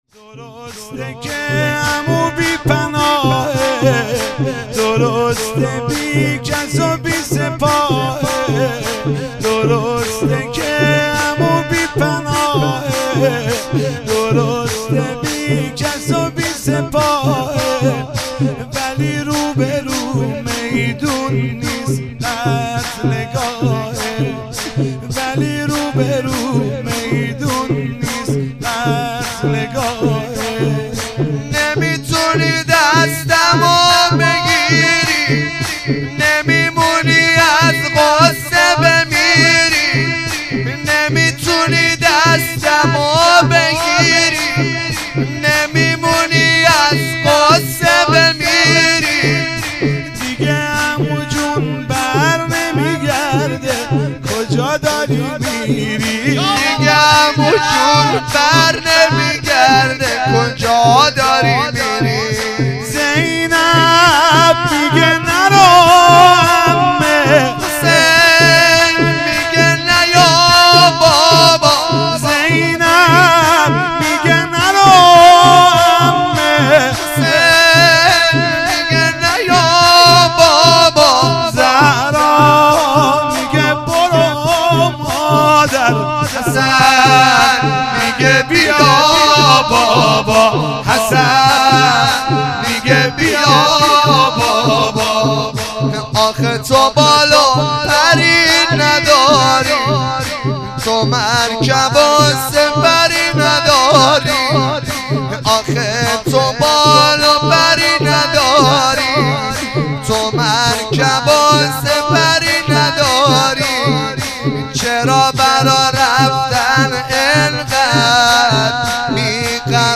نوحه شور درسته که عمو بی پناهه